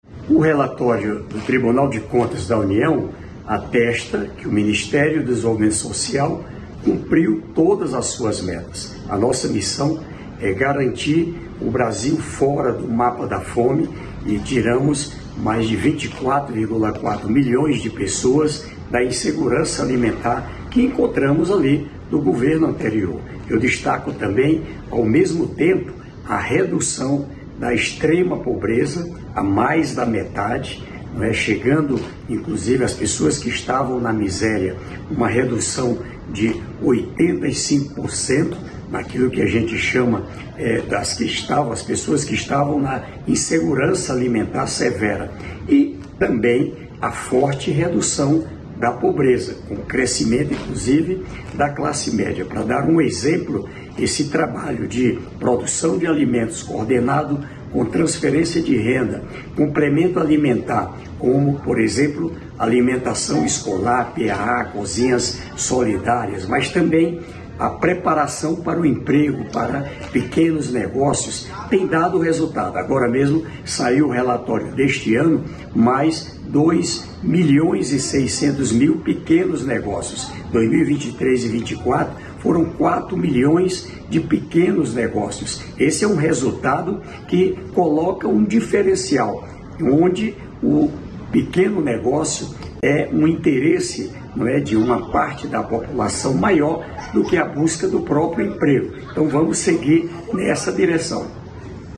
Íntegra do discurso da ministra dos Direitos Humanos e Cidadania, Macaé Evaristo, empossada pelo presidente Lula, nesta sexta-feira (27), em Brasília.